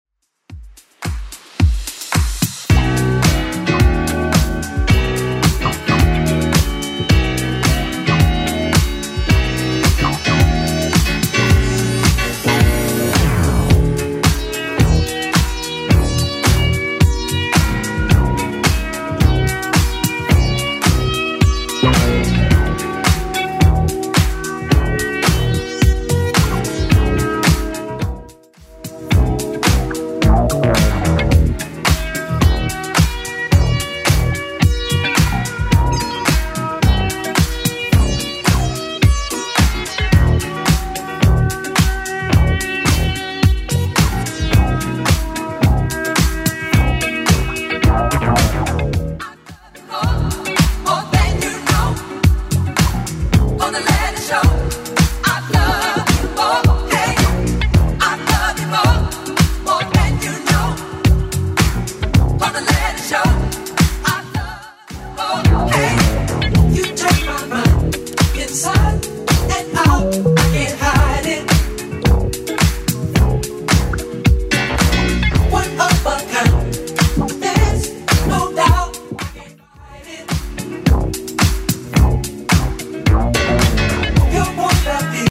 Genre: 90's Version: Clean BPM: 136